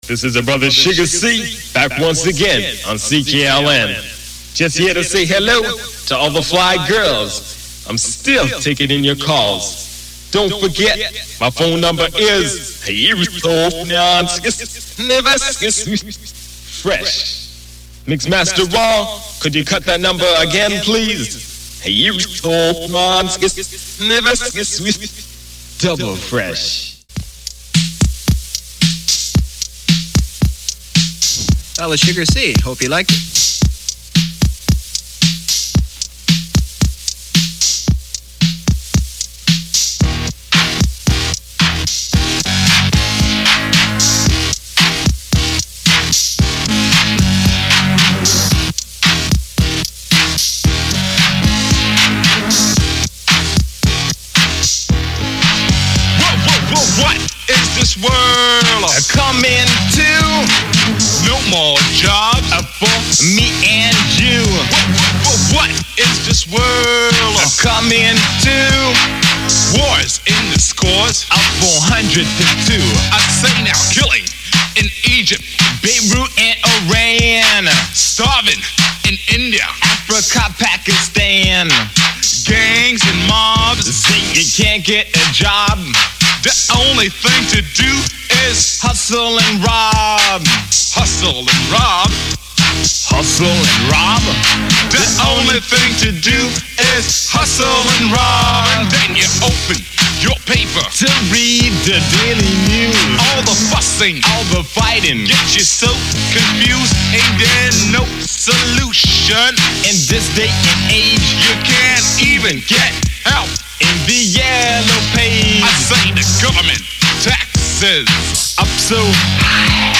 Rap record
beatbox drum machine
it was a socially conscious, reality-driven piece